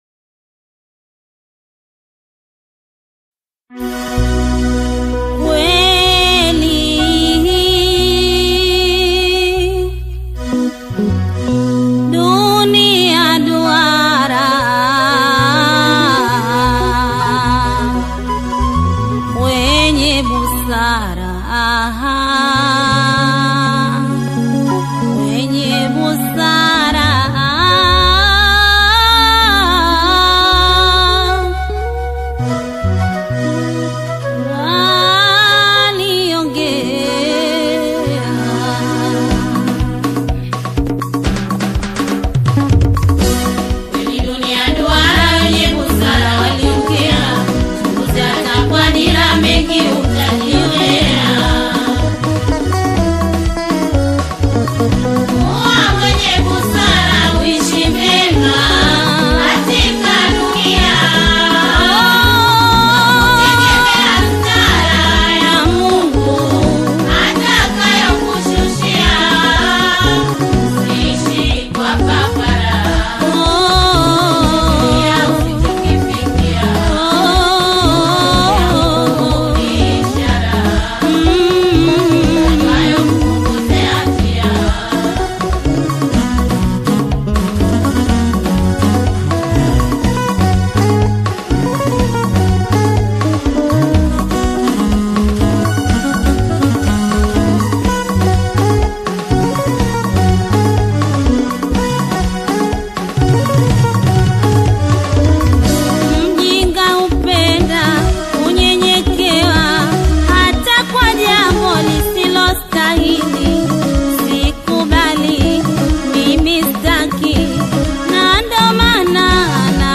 In this soulful composition
enchanting vocals blend seamlessly with poignant lyrics